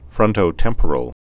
(frŭntō-tĕmpər-əl, -tĕmprəl)